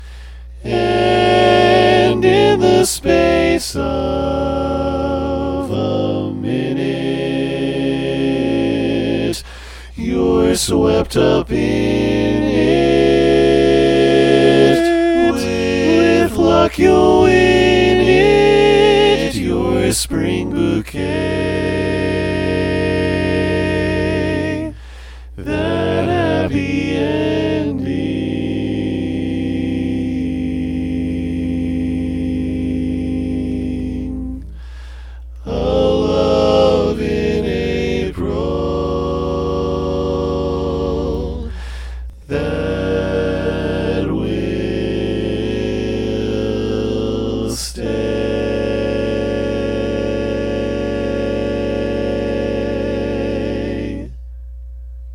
Key written in: D♭ Major
How many parts: 4
Type: Barbershop
Comments: Tenor melody!
All Parts mix: